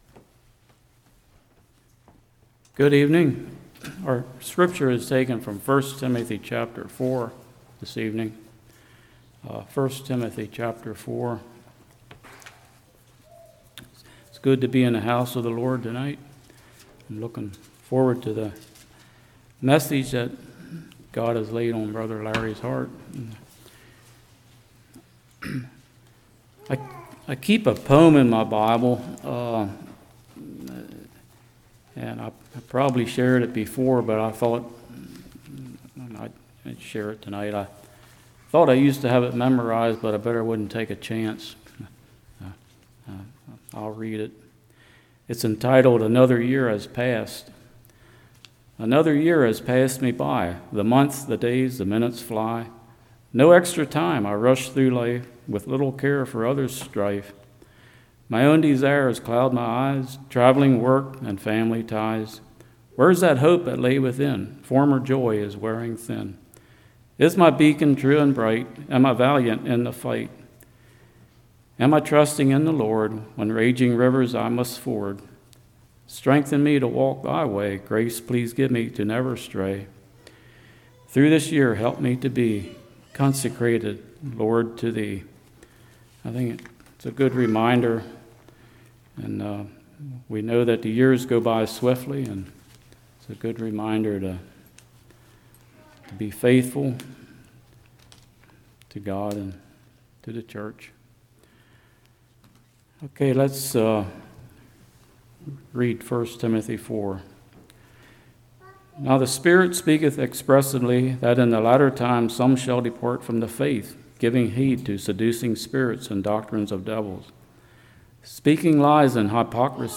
1 Timothy 4 Service Type: Evening Be steadfast